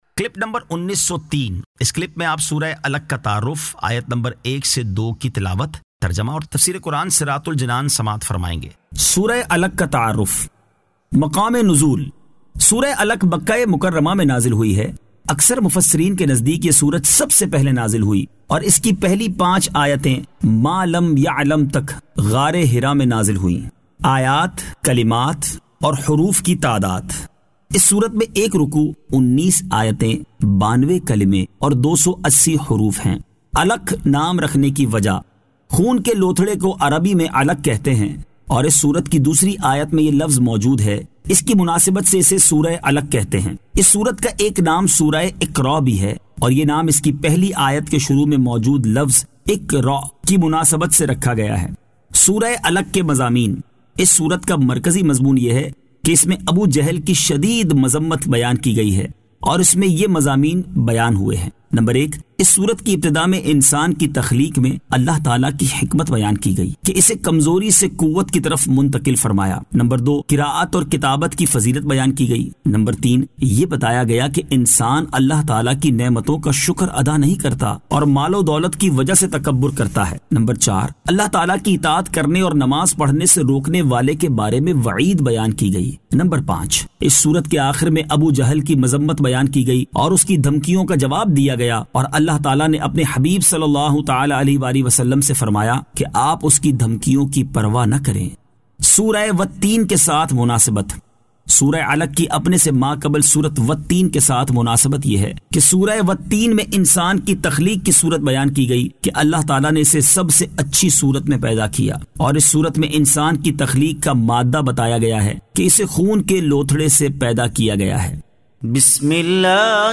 Surah Al-Alaq 01 To 02 Tilawat , Tarjama , Tafseer